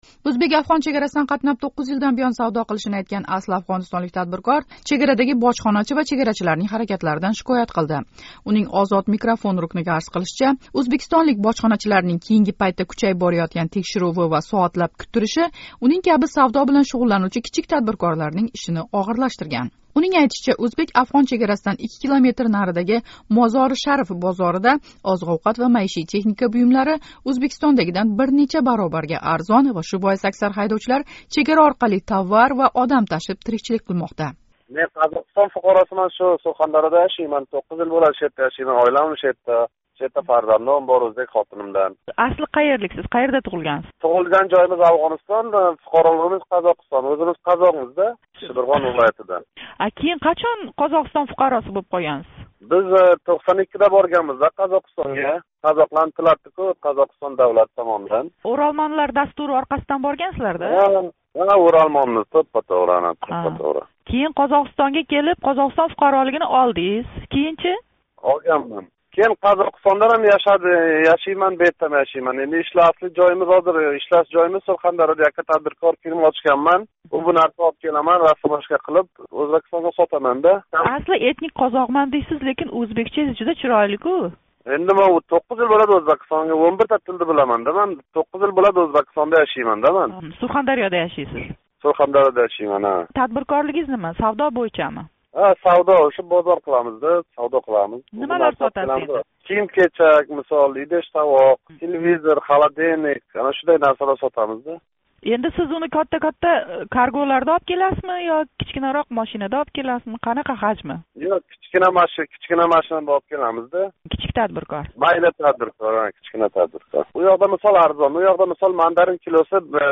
Қуйида бу 9 йилдан буён Сурхондарёда яшаётган афғонистонлик кичик тадбиркор билан суҳбатни тингланг: